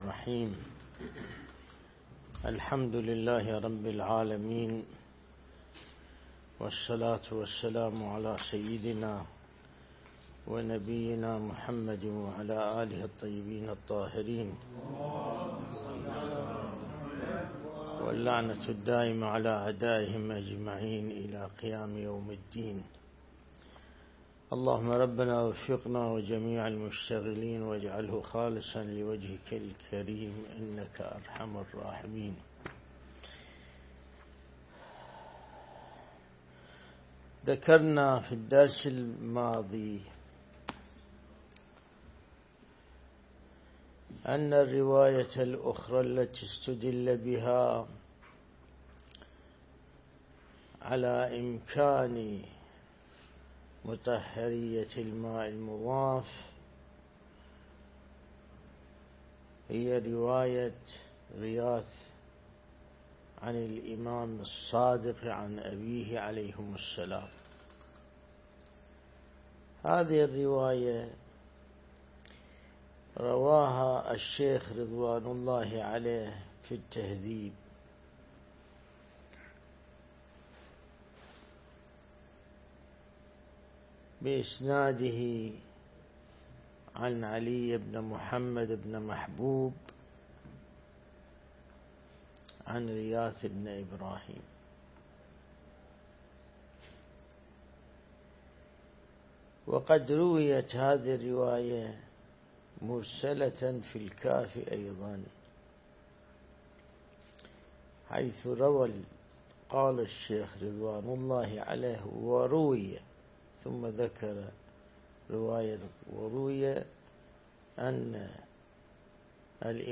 درس (62) | الدرس الاستدلالي